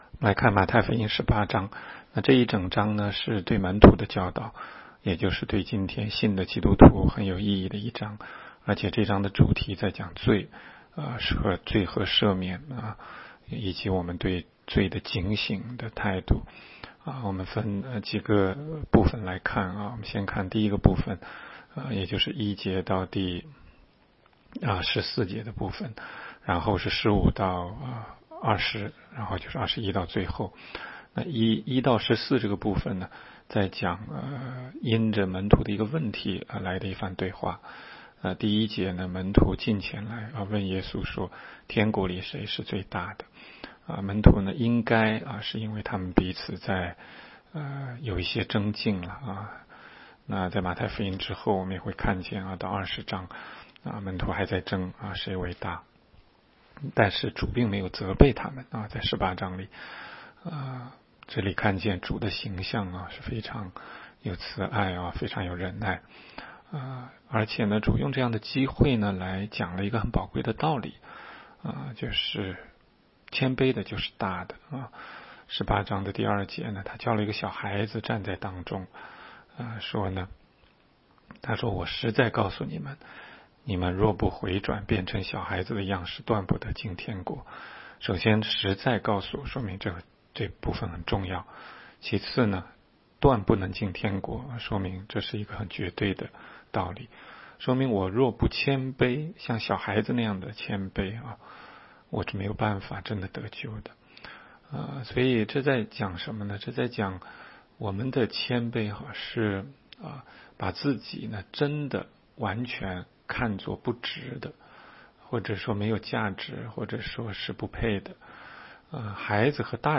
16街讲道录音 - 每日读经-《马太福音》18章